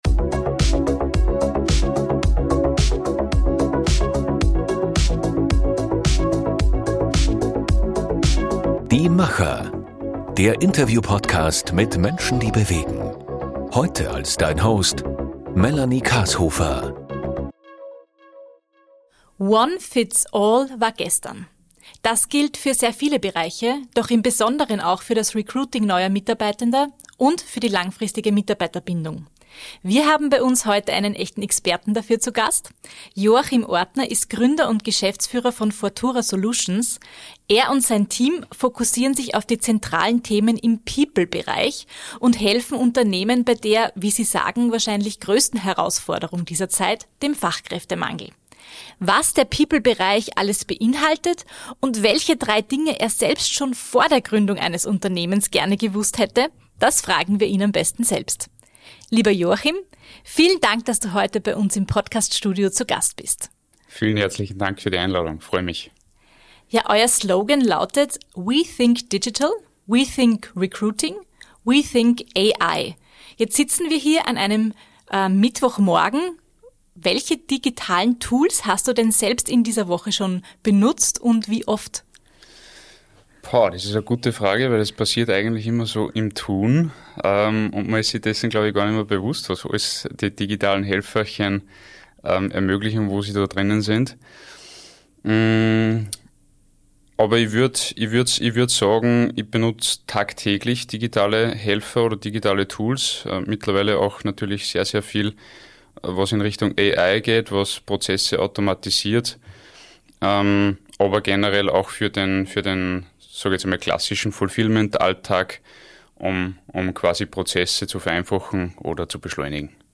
One-fits-all war gestern Das gilt für viele Bereiche, doch im Besonderen auch für das Recruiting neuer Mitarbeitenden und die langfristige Mitarbeiterbindung. In unserer neuen Podcastfolge haben wir dazu einen echten Experten zu Gast.